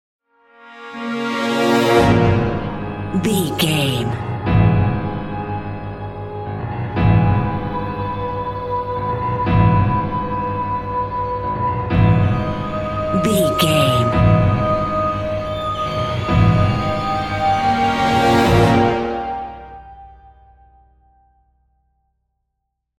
Thriller
Atonal
scary
tension
ominous
dark
suspense
dramatic
eerie
strings
brass
horror
cymbals
gongs
viola
french horn trumpet
taiko drums
timpani